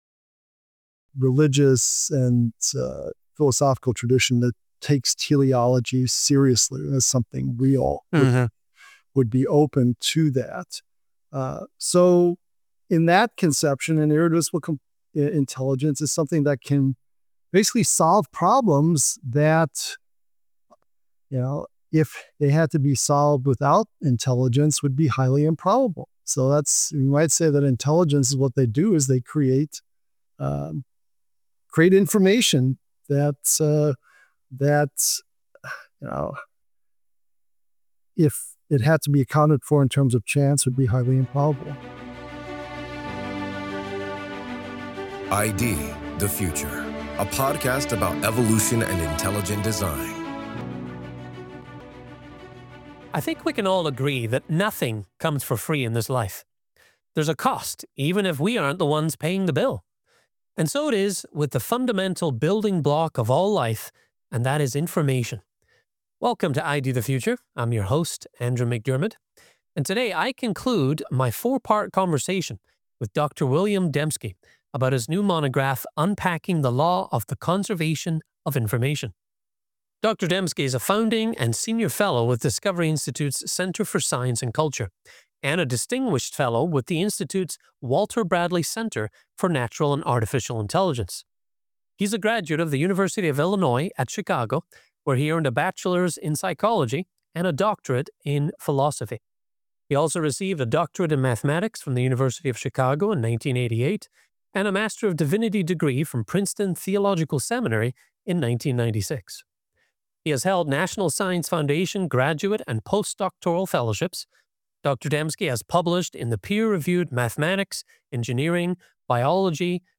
In this final segment, Dembski explains the ultimate origin of information: what he calls irreducible intelligence.